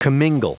Prononciation du mot commingle en anglais (fichier audio)
Prononciation du mot : commingle